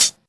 Closed Hats
Hi Hat [Scooot].wav